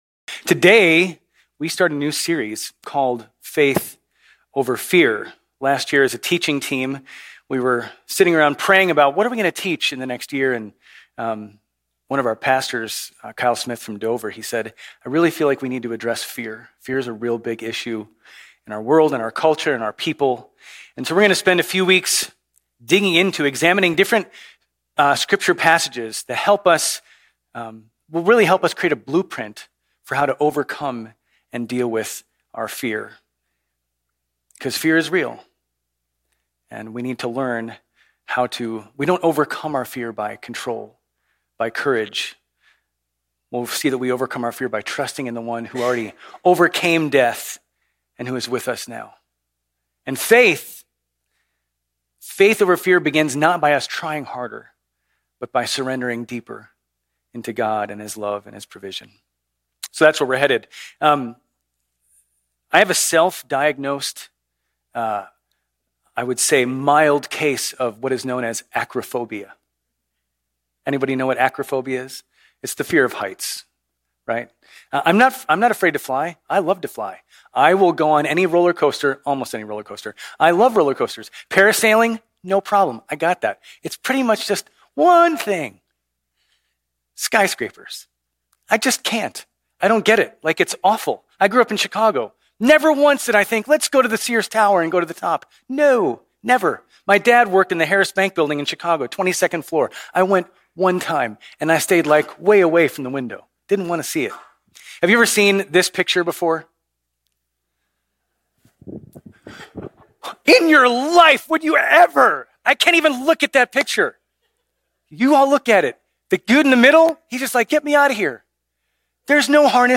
Grace Community Church Old Jacksonville Campus Sermons 4_27 Old Jacksonville Campus Apr 27 2025 | 00:30:34 1x 00:00 / 00:30:34 Subscribe Share RSS Feed Share Link Embed